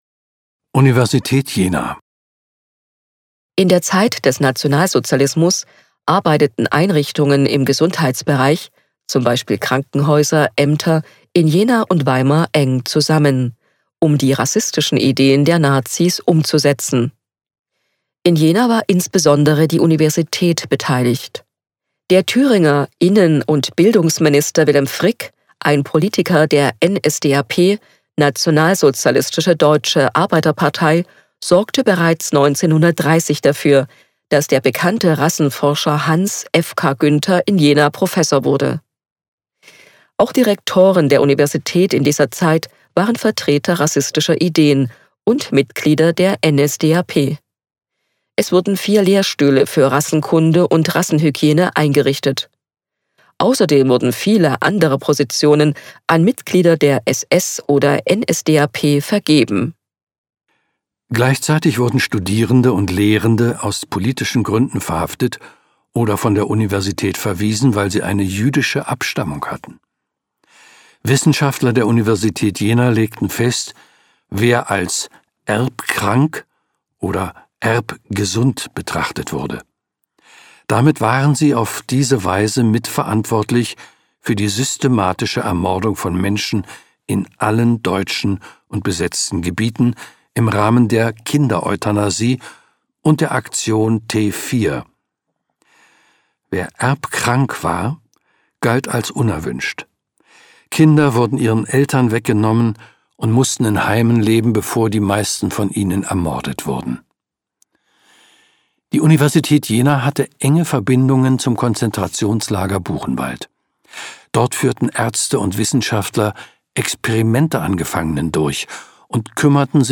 Hörbeitrag - Universität Jena im Nationalsozialismus